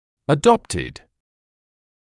[æ’dɔptɪd][э’доптид]приёмный; усыновлённый, удочерённый